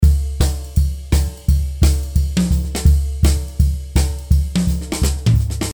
With live jazz drum loops that feature brushes, sticks and rods you’ll get a wide range of percussive tones with this download.
As you’ll hear in the demo, the kick drum is soft and has a clearly defined ‘note’ to give it character.
The tom toms were tuned quite high and had little to no dampening while the snare drum was tuned mid range with only a small amount of dampening and LOTS of snare rattle on the underside of the drum.
There’s enough room ambience to give the loops a live ‘small club’ sound without adding too much colouration to the recording.
When you’re ready for the full band to drop in with all instruments on deck use a full drum loop like the one below to give the song some organic motion.
swing_168_fill2.mp3